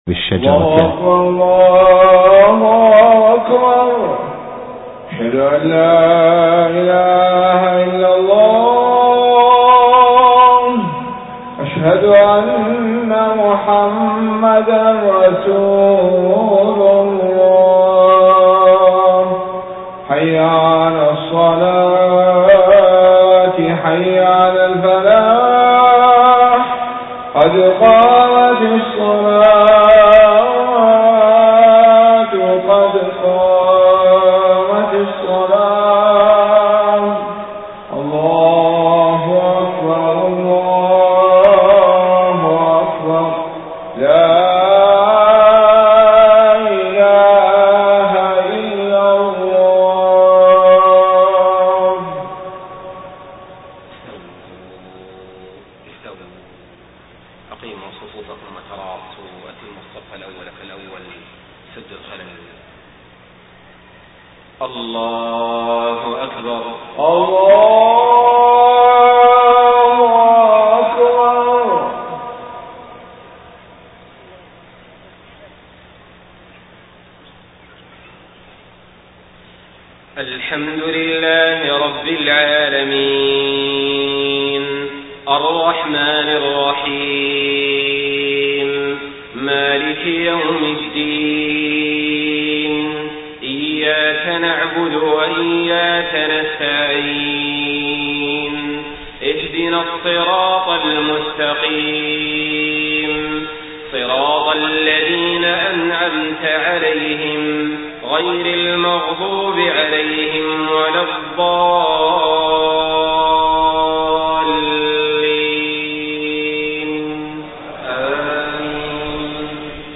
صلاة العشاء 5 ربيع الأول 1431هـ من سورتي المؤمنون 57-62 و يس 55-59 > 1431 🕋 > الفروض - تلاوات الحرمين